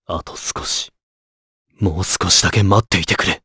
Clause_ice-Vox_Victory_jp.wav